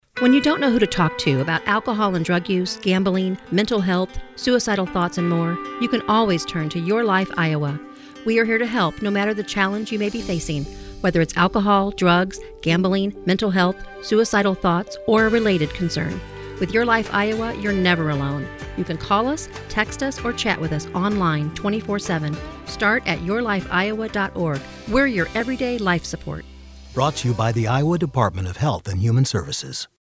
:30 Radio Spot | YLI Awareness (Female-1)